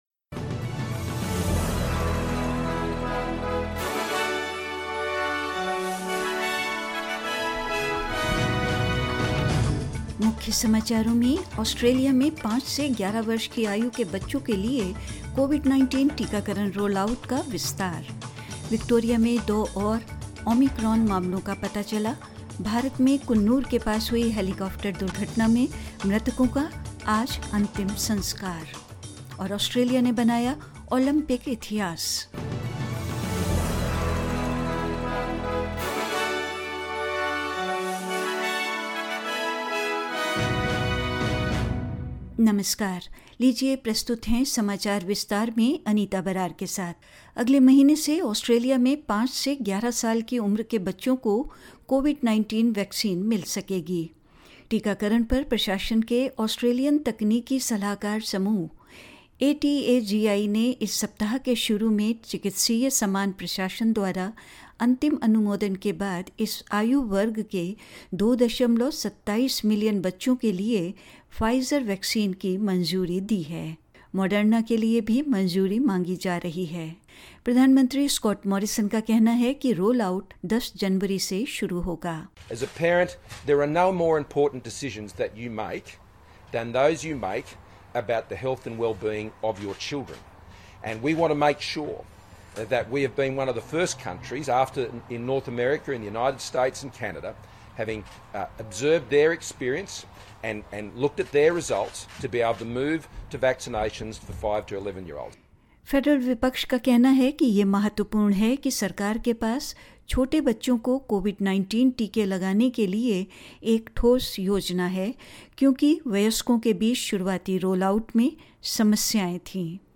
In this latest SBS Hindi news bulletin of Australia and India: Australia is expanding its COVID-19 vaccination rollout with children aged five to 11 now eligible for Pfizer; Two more Omicron cases detected in Victoria; In India PM Modi pays tribute to CDS Gen Bipin Rawat and other defence personnels who lost their lives in the IAF chopper crash on wednesday and more.